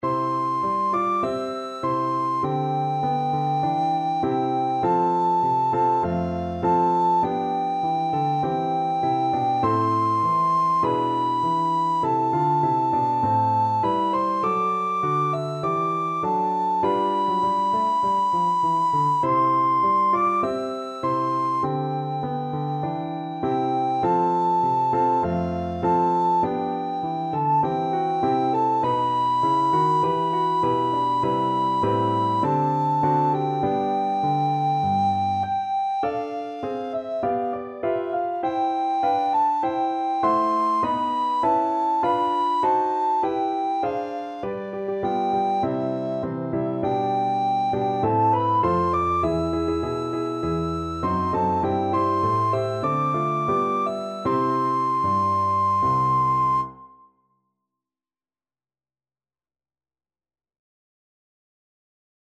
Free Sheet music for Soprano (Descant) Recorder
Recorder
irish_nat_anth_REC.mp3